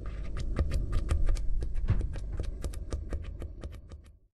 Sound / Minecraft / liquid / lava / lavapop.ogg
lavapop.ogg